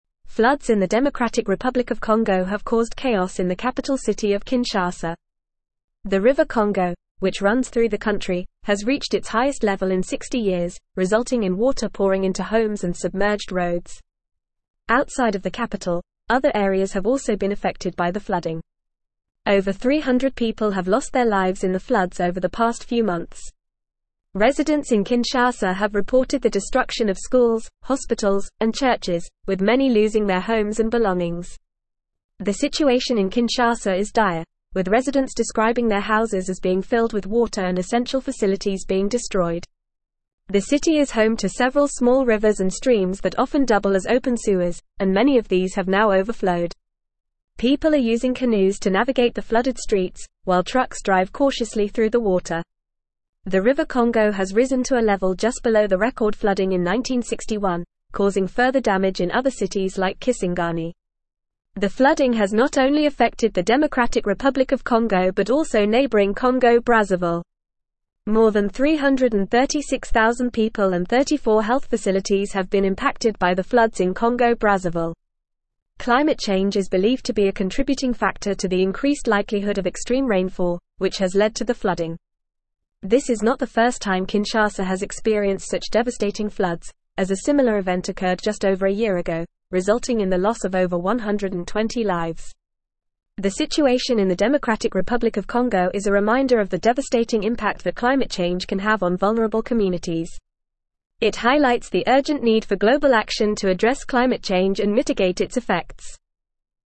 Fast
English-Newsroom-Advanced-FAST-Reading-Congos-Capital-Kinshasa-Devastated-by-Record-Floods.mp3